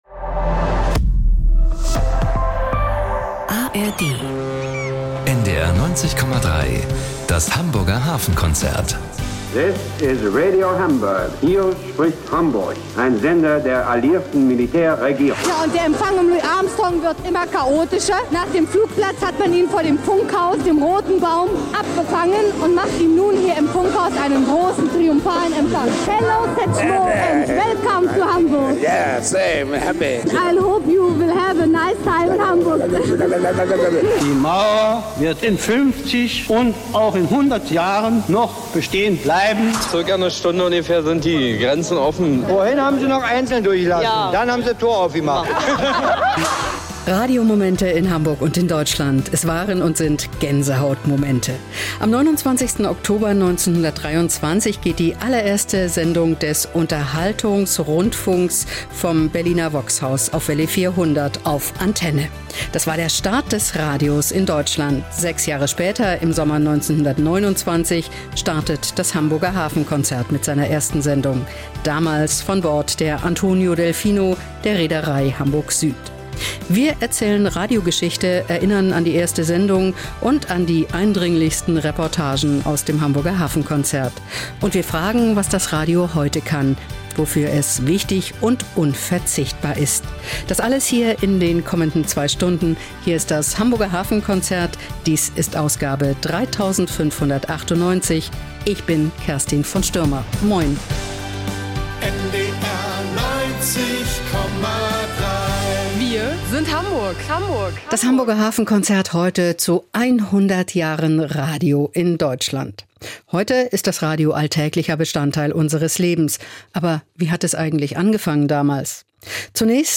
Reportagen über wichtige maritime Ereignisse sind genauso zu hören wie Interviews mit Kapitänen und Hafenarbeitern.